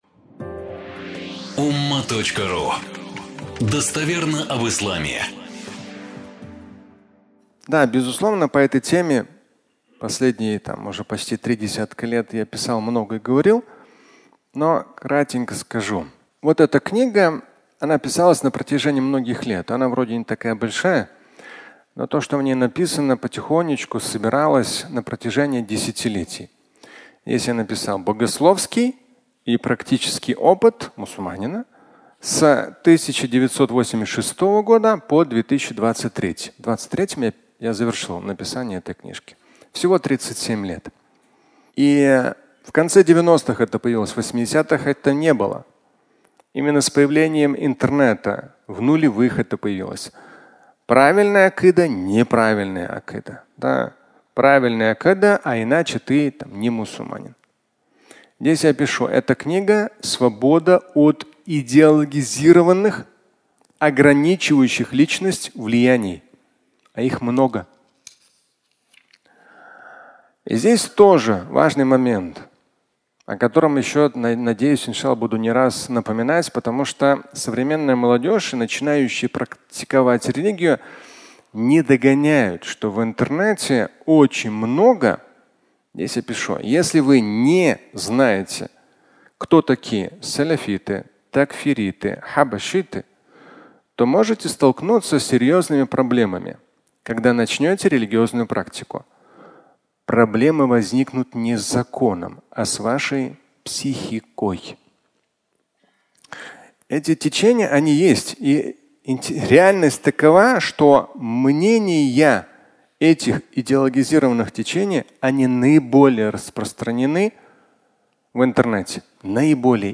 Религия и секты (аудиолекция)